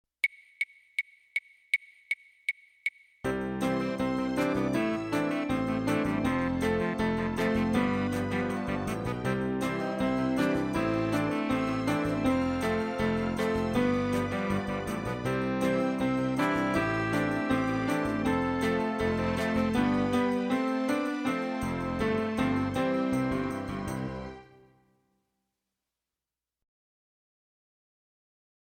Voicing: Piano w/ Audio